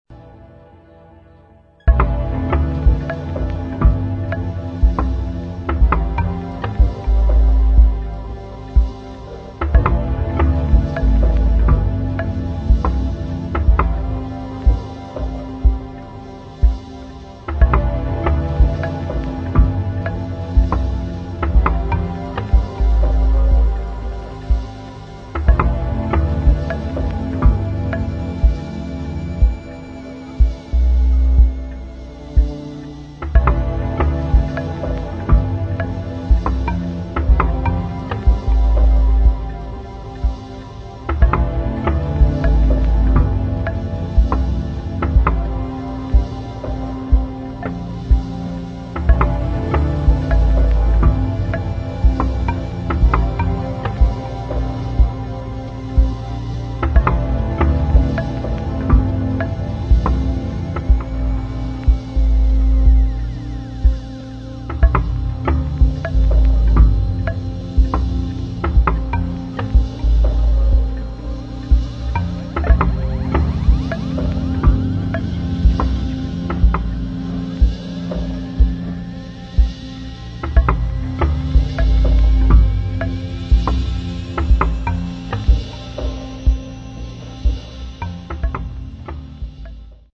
[ DEEP HOUSE / EXPERIMENTAL / TECHNO ]